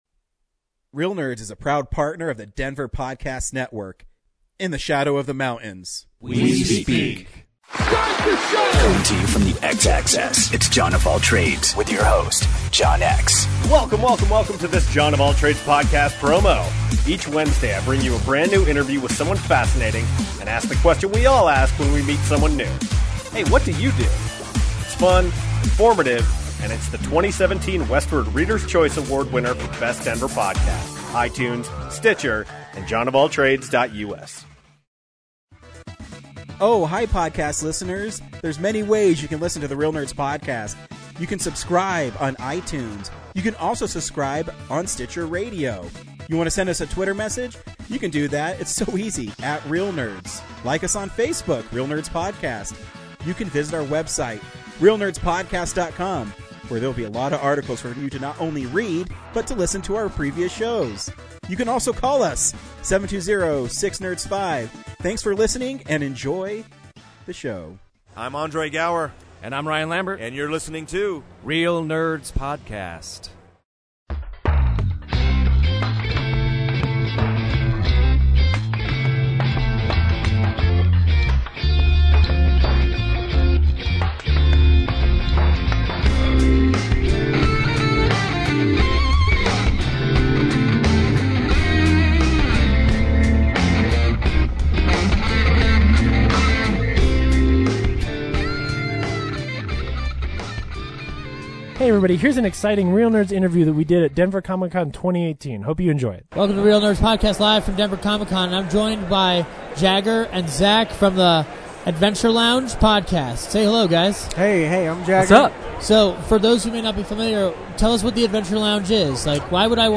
Reel Interview: The Adventure Lounge - Reel Nerds Podcast
The Reel Nerds chat with The Adventure Lounge at Denver Comic Con 2018.